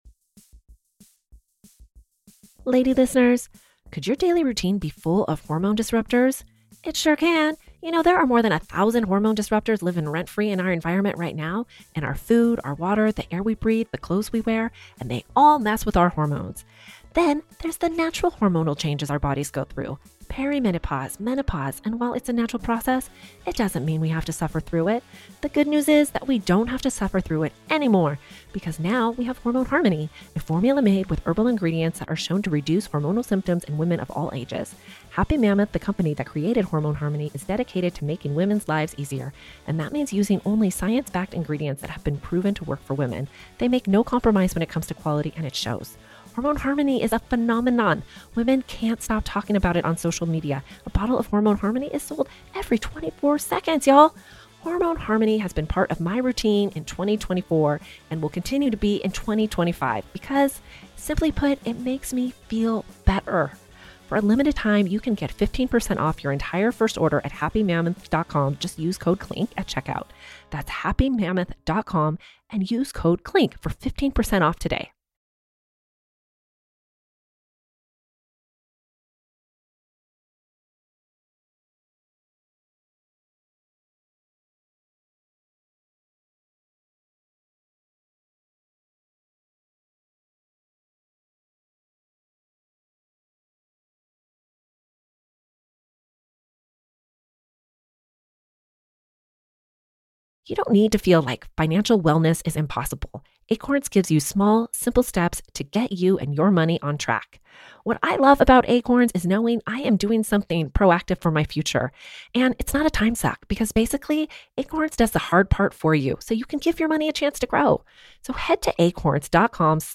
Every week one of the two hosts will tell a true crime favorite while the other host asks the questions everyone wants to know. We are talking crime in the carpool line.